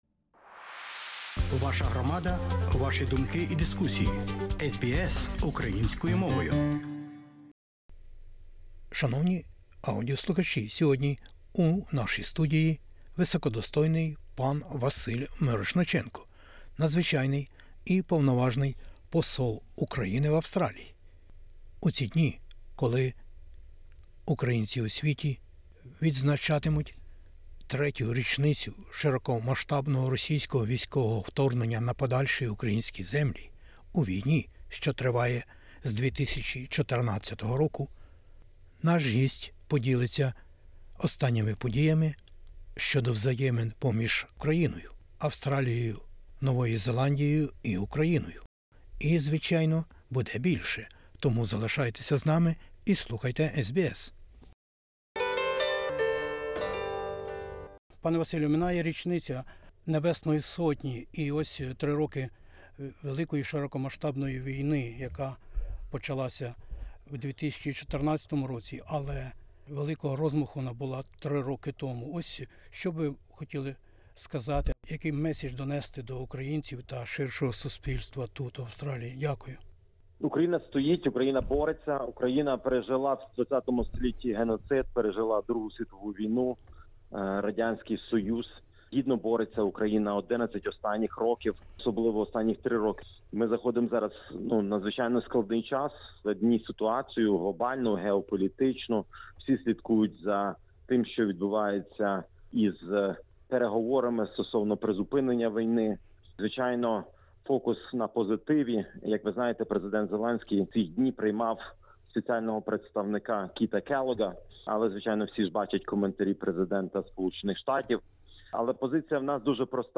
У розмові з SBS Ukrainian Надзвичайний і Повноважний Посол України в Австралії Василь Мирошниченко розповідає, зокрема, про взаємини України з Австралією та Новою Зеландією, їхню постійну підтримку українців напередодні 3-ої річниці широкомасштабного російського військового вторгнення на подальші українські землі.